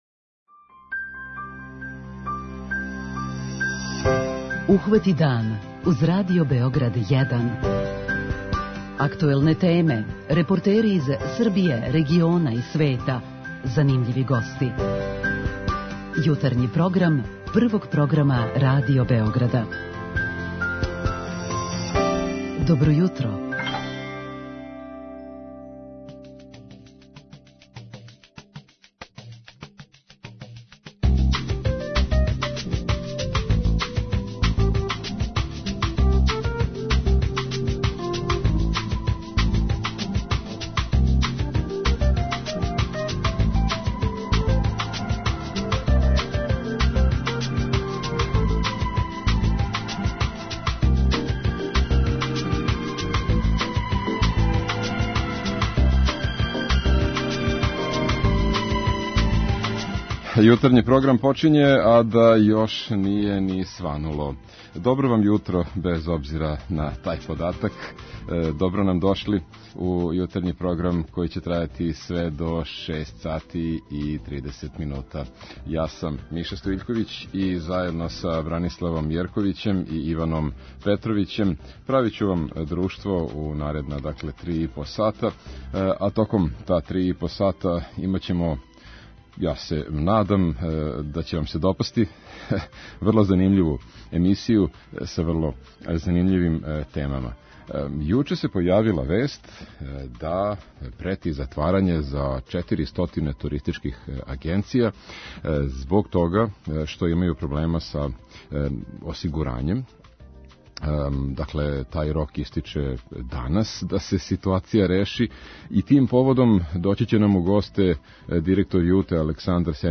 О безбедности бициклиста разговараћемо и с нашим слушаоцима у редовној рубрици "Питање јутра".